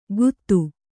♪ guttu